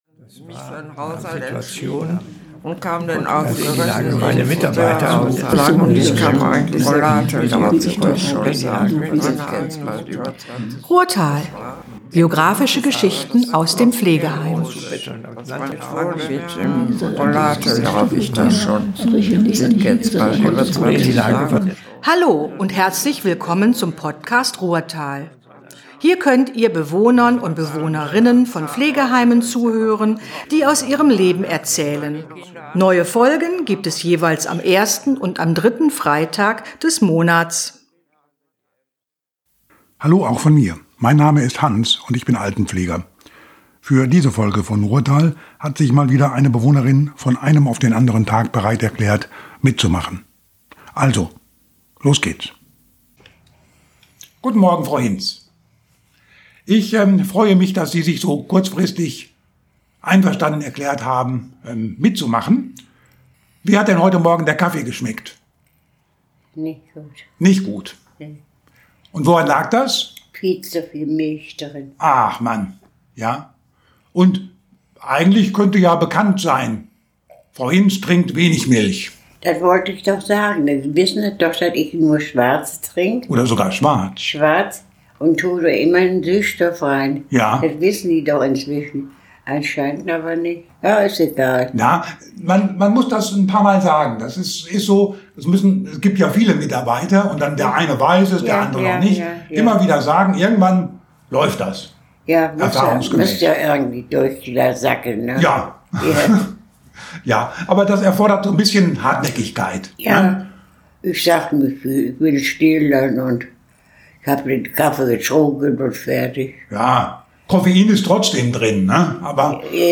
Freut euch auf ein tolles Gespräch über eine tolle Schule.